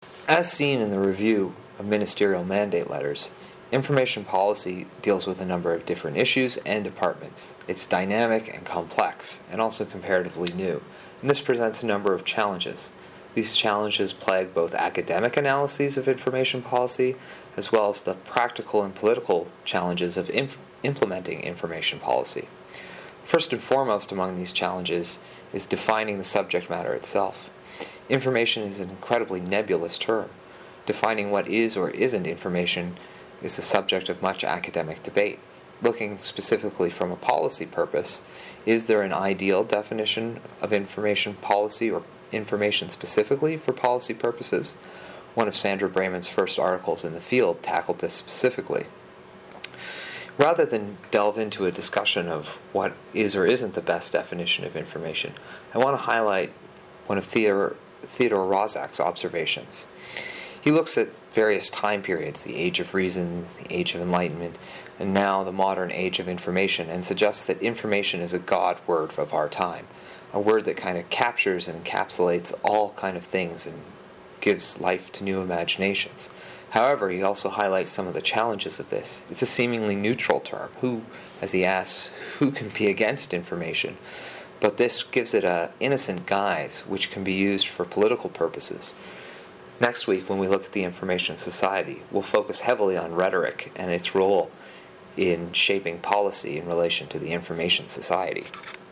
These are the underlying files (PowerPoint and audio) for Lecture 1 of LIS 598 Information Policy, Winter 2017.